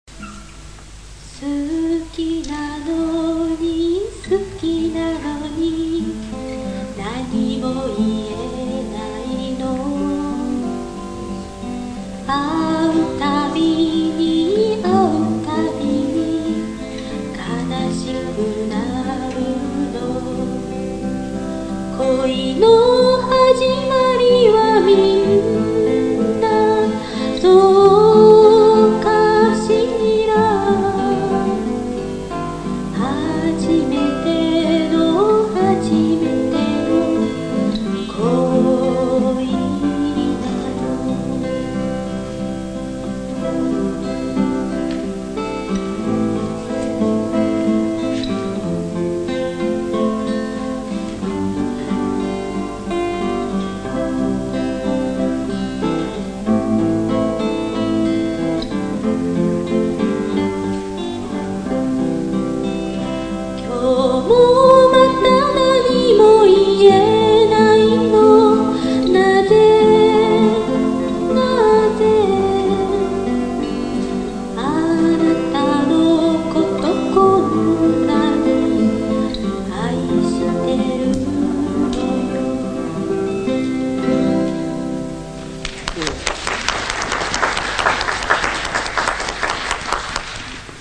ギター -オリジナル曲-
ライブ版。
バンドは男２人と女３人。
楽器はギター３台とタンバリン他で、アコースティックが中心でした。
当時のテープ録音をサウンドレコーダーで編集したので、音はモノラルです。